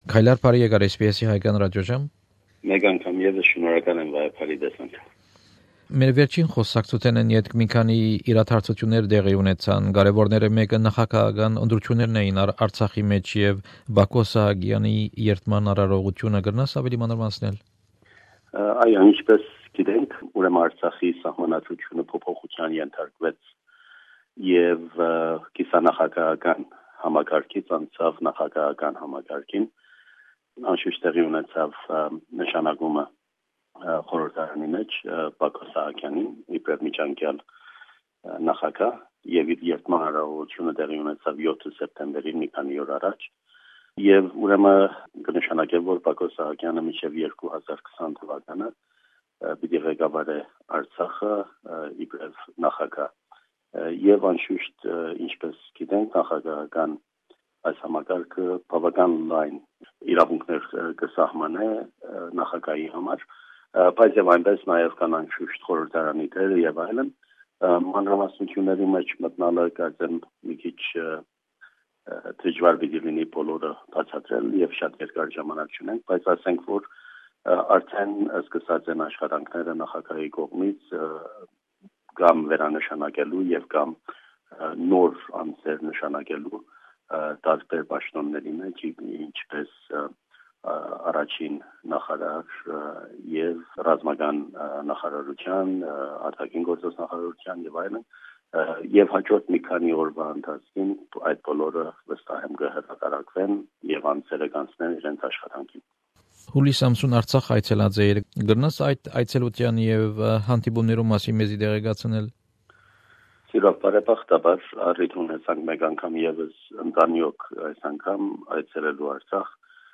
An interview with Mr Kaylar Michaelian, the Permanent Representative of Artsakh Republic to Australia, about the recent presidential elections, his visit to Artsakh and the upcoming Armenia-Diaspora conference.